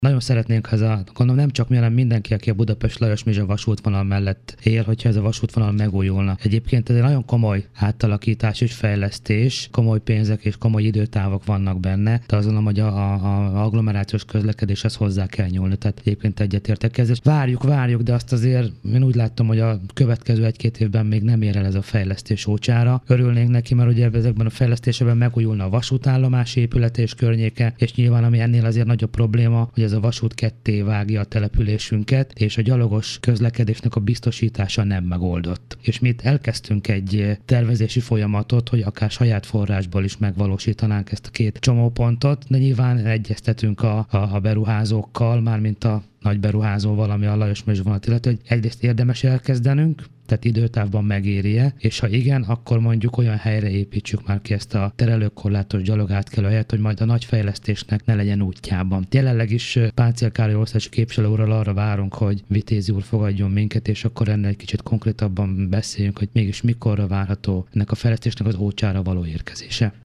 Hajnal Csilla, Pilis polgármestere rádiónkban azt mondta, a morál időnként gyengül.